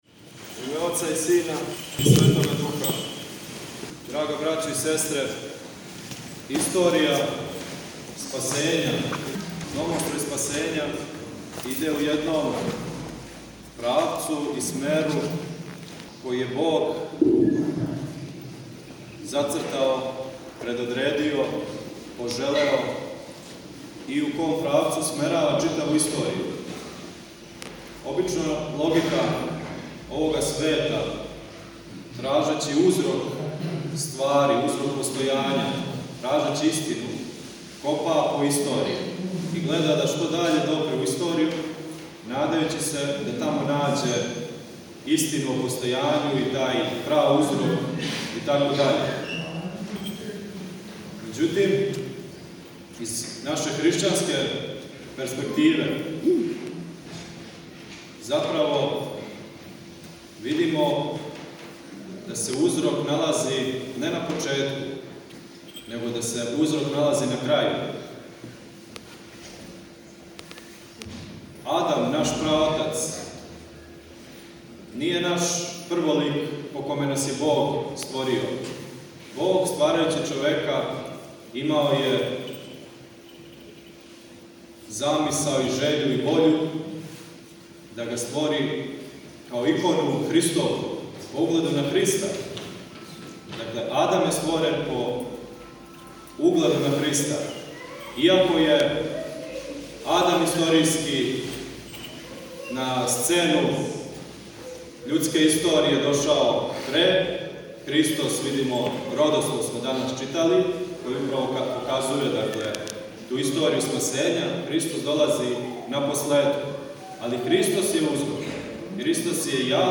Његово Преосвештенство викарни Епископ топлички г. Петар, служио је у недељу 29. по Духовима, 01. јануара 2023. године, свету архијерејску Литургију у храму Светог Вазнесења Господњег у центру Београда.
Звучни запис беседе